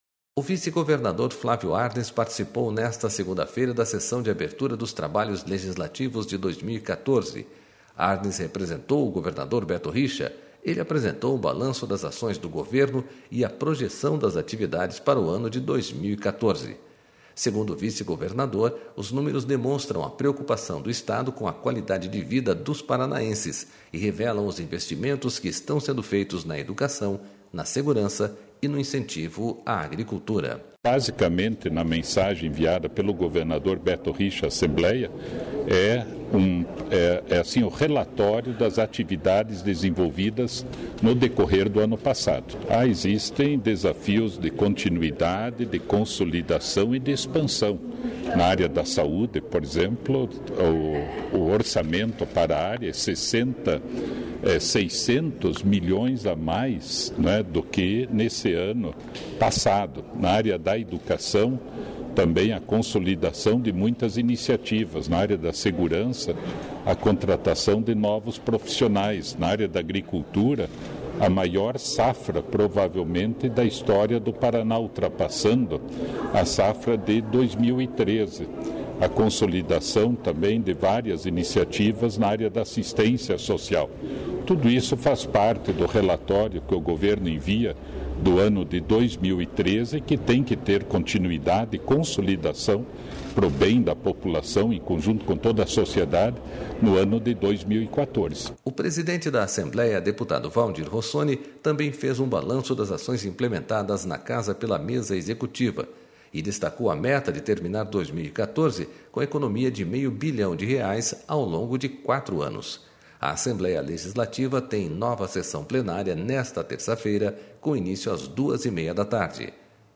Flávio Arns apresenta balanço das ações do Governo na primeira sessão deste ano na Assembleia Legislativa